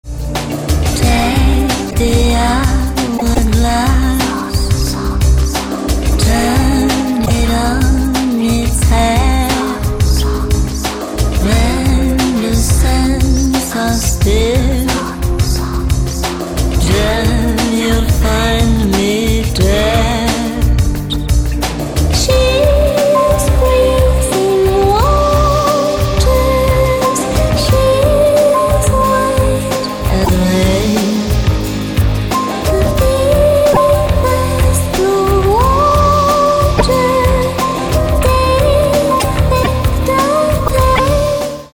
Vocals
Keyboards, Guitars, Bass & Programming